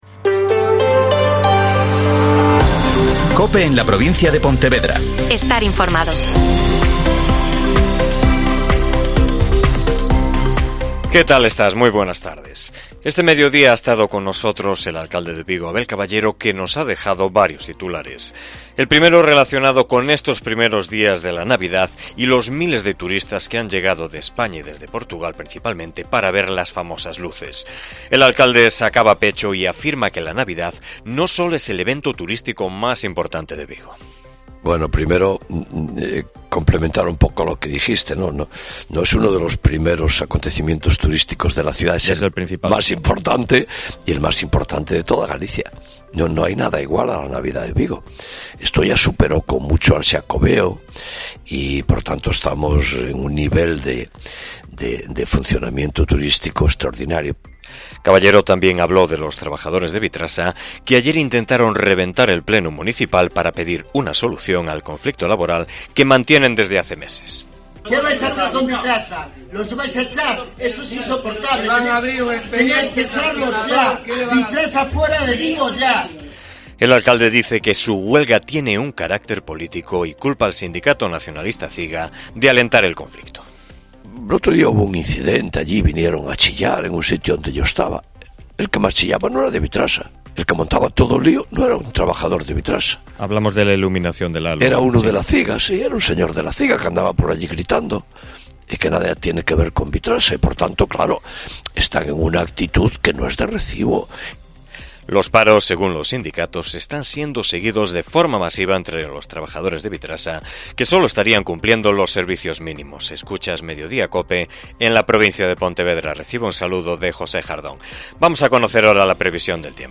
AUDIO: Informativo provincial